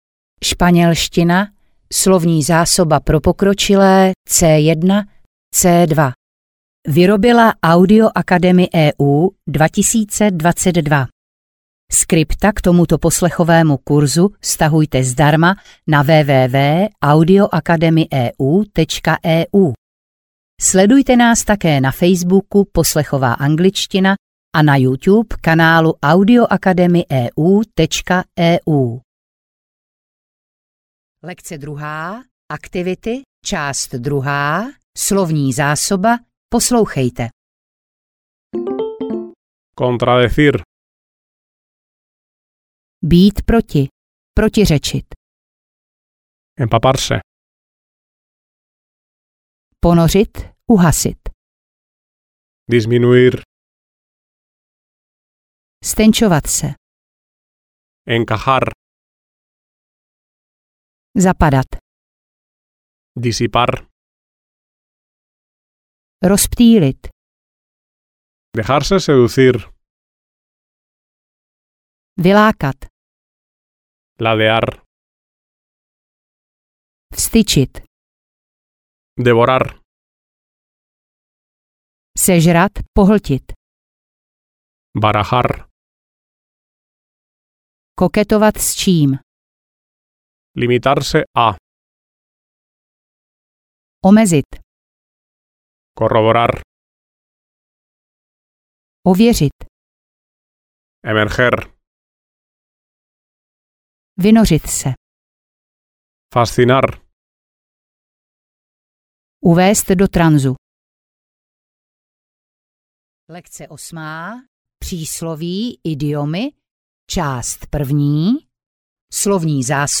Audio knihaŠpanělština pro pokročilé C1-C2